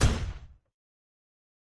pgs/Assets/Audio/Sci-Fi Sounds/MISC/Footstep Robot Large 2_03.wav at master
Footstep Robot Large 2_03.wav